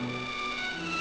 Added violin